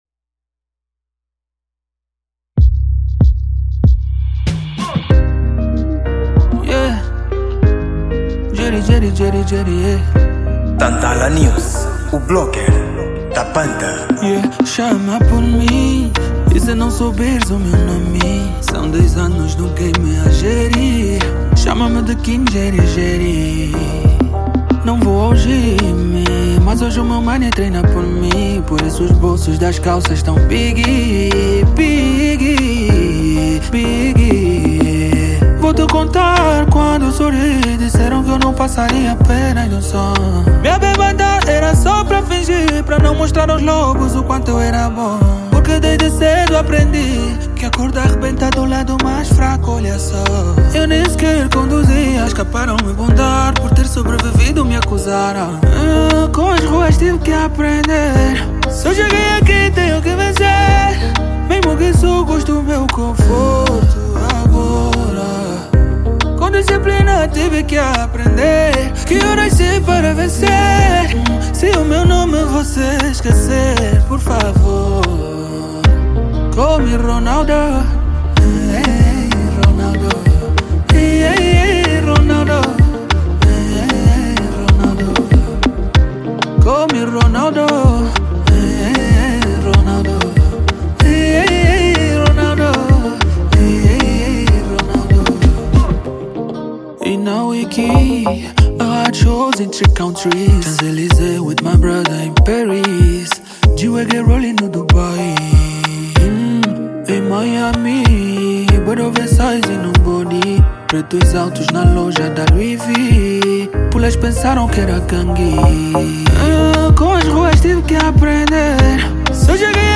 Género: Afro Pop